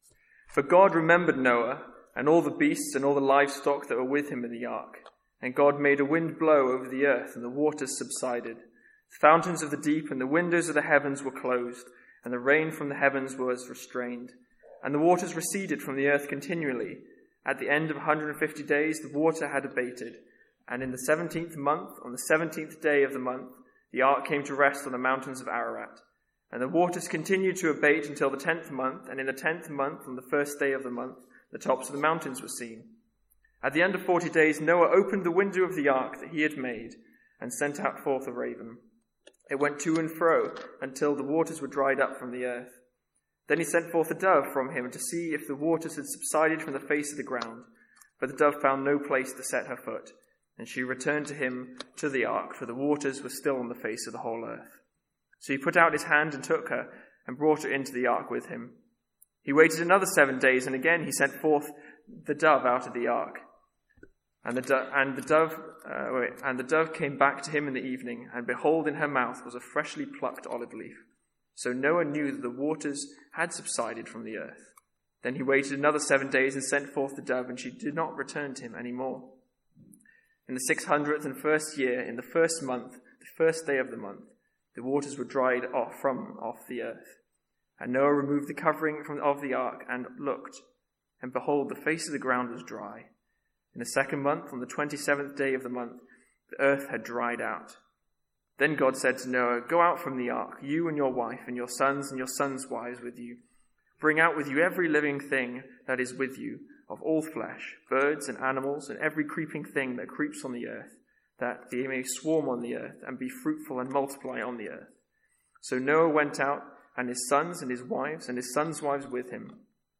Sermons | St Andrews Free Church
From our evening series in Genesis.